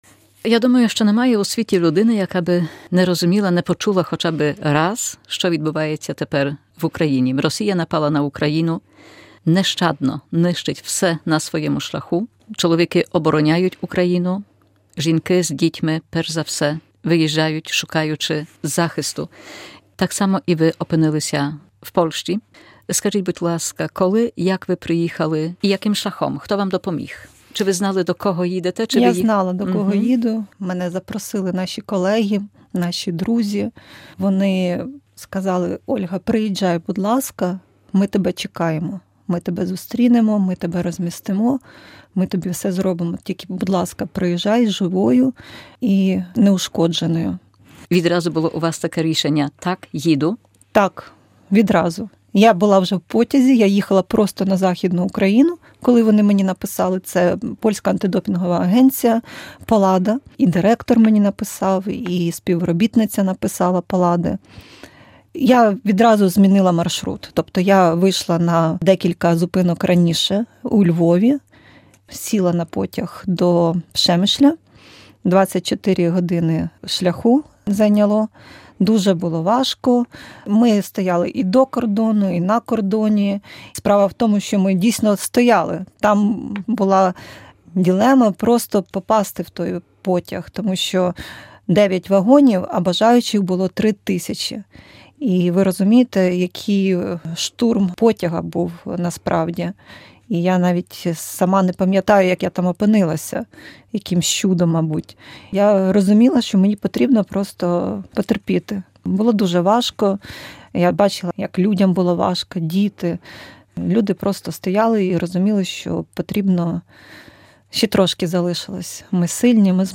rozmawiała